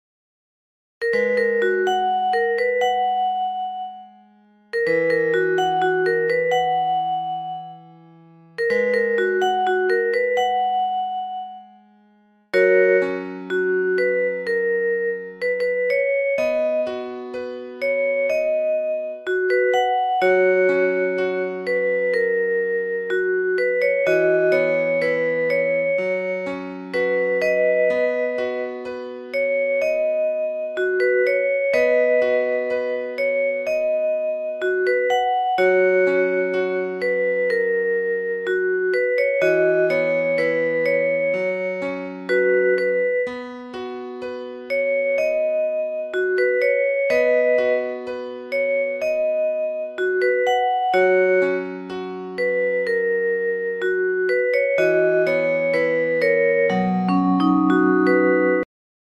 club anthem